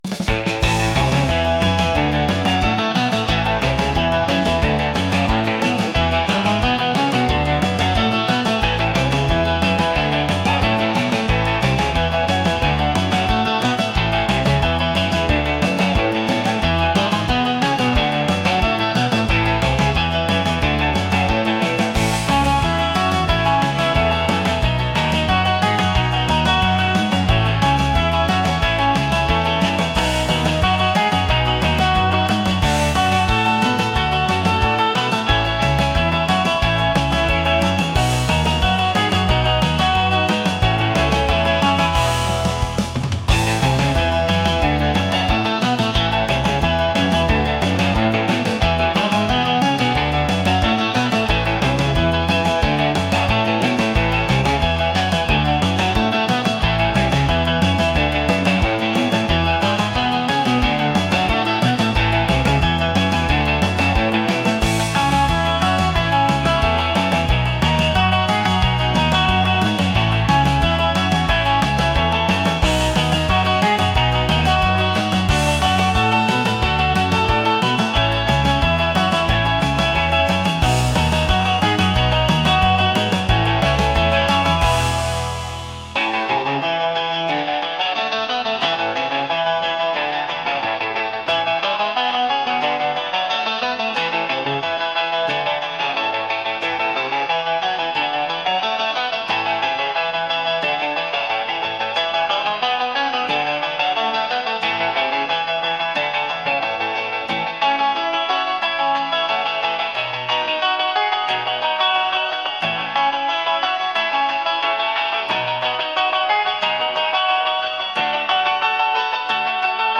upbeat | energetic | rock